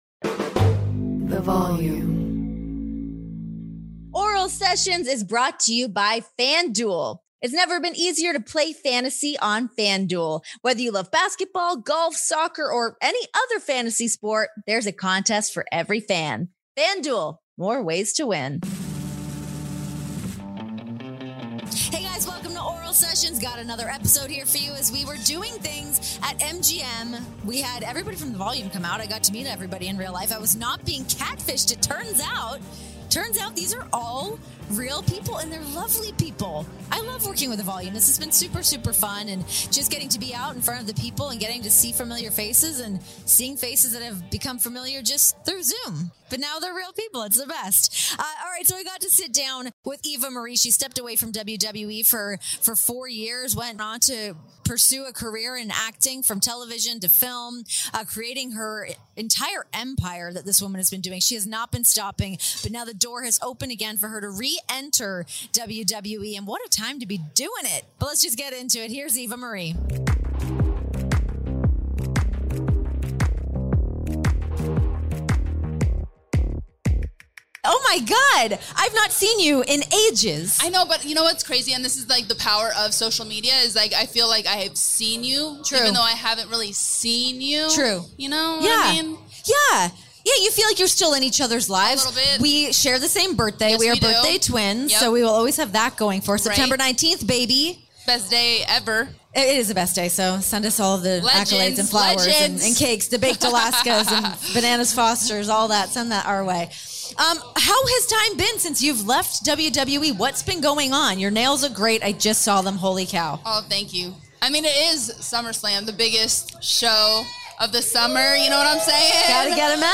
Miss All Red Everything herself joins the pod for a conversation spanning one of the most unique WWE careers in recent memory that includes debuting on a reality show, harnessing the power of nuclear heat, and a stint in Hollywood that saw her rub elbows with the likes of Nicolas Cage and Bruce Willis. Oh, and we talk about Doudrop, too.